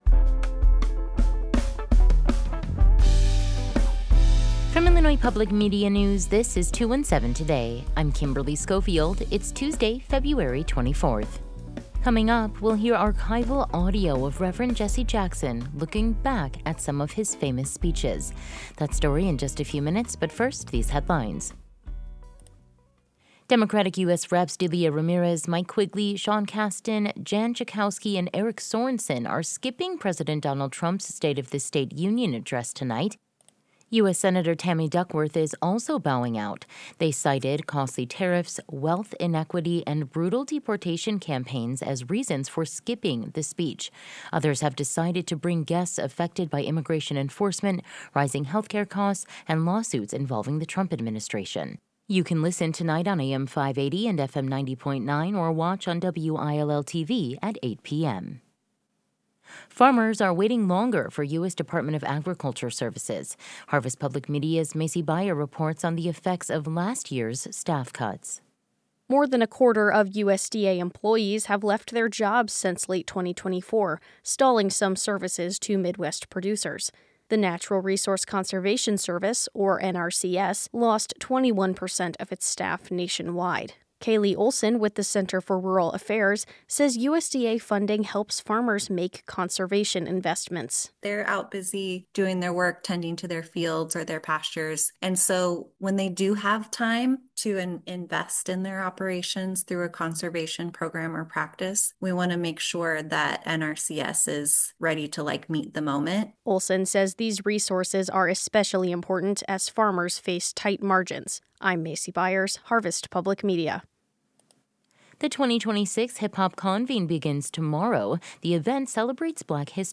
We’ll hear archival audio of Reverend Jesse Jackson looking back at some of his famous speeches.